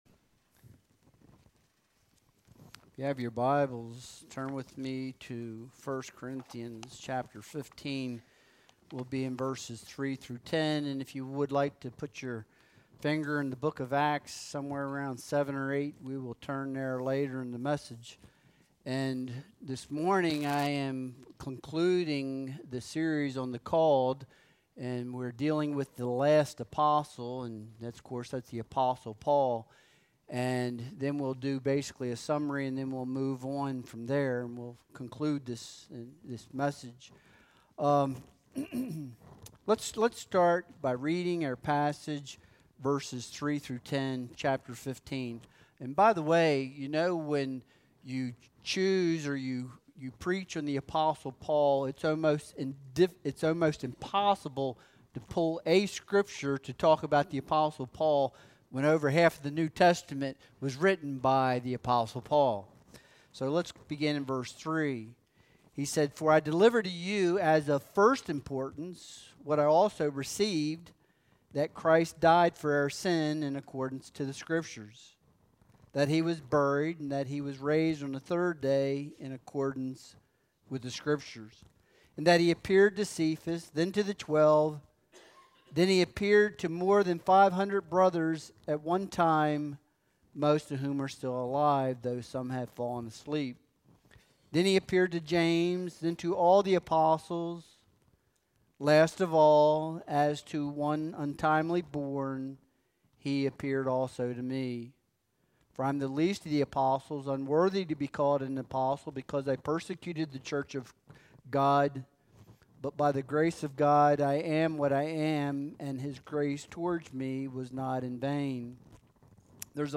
1 Corinthians 15.3-10 Service Type: Sunday Worship Service « The Power Behind the Call What Impression has Jesus Made on You?